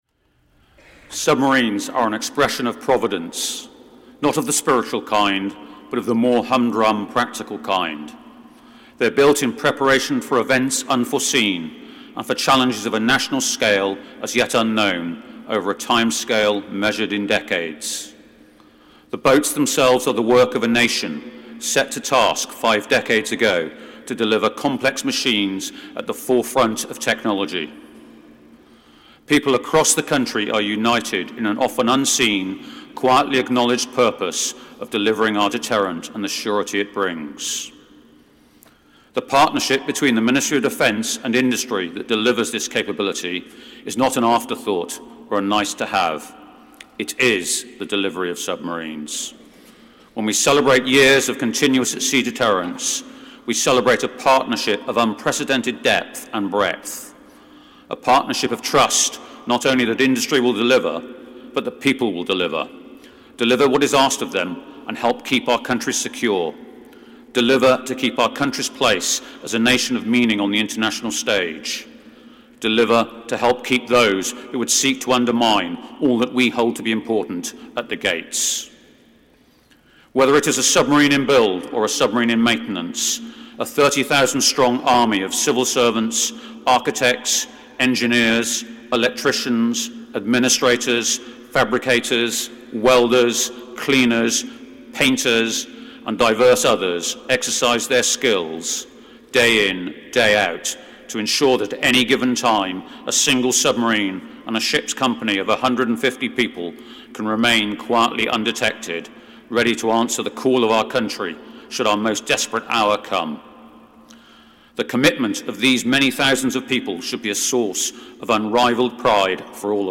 Rear Admiral Tim Hodgson MBE, Director Submarine Capability, Ministry of Defence, gives a Testimony at a service to recognise fifty years of Continuous At Sea Deterrent at Westminster Abbey at Noon on Friday 3rd May 2019.